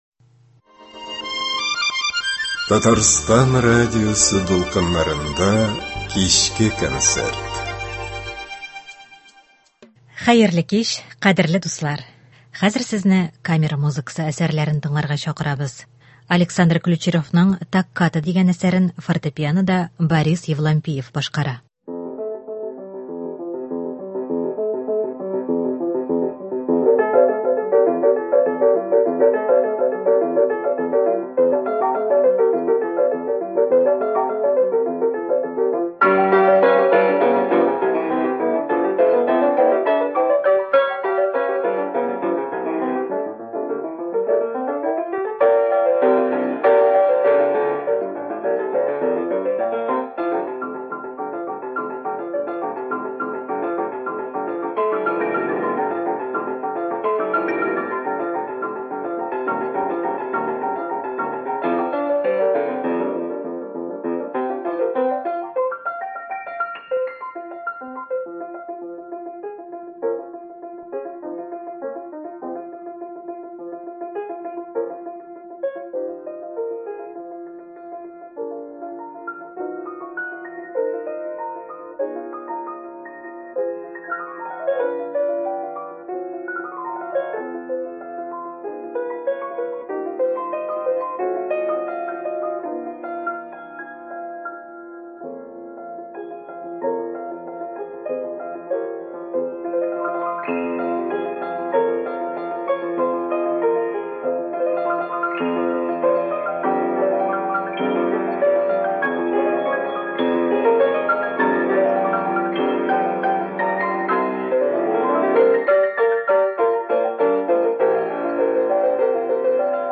Камера музыкасы концерты